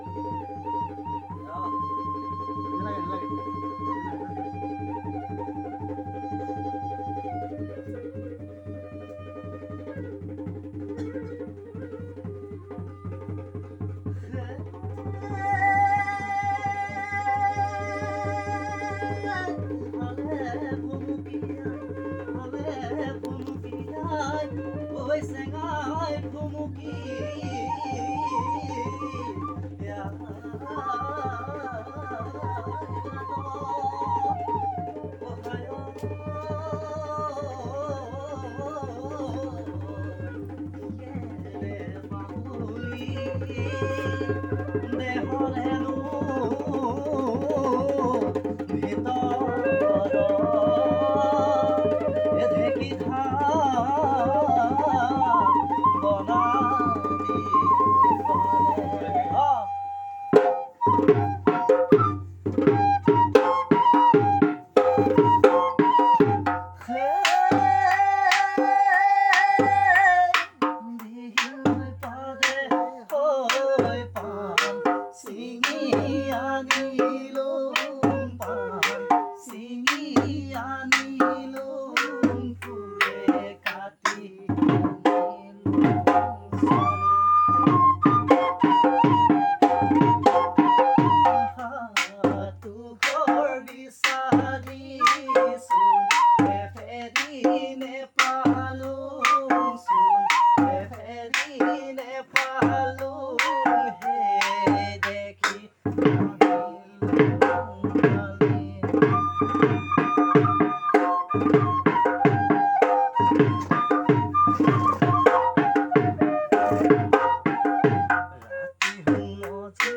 Playing with folk song